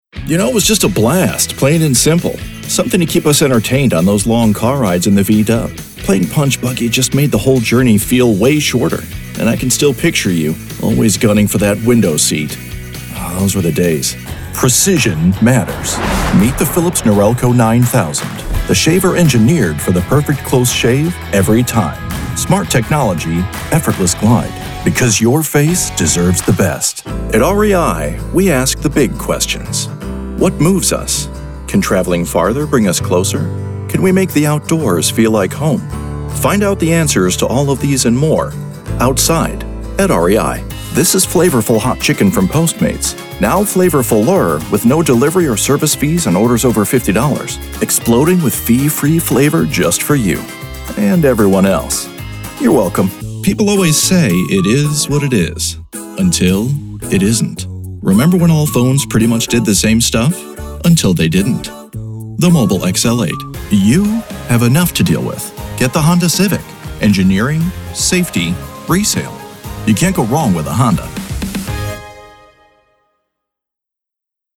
Warm and friendly tone, with a knowledgeable feel
Commercial Demo
English - USA and Canada
Middle Aged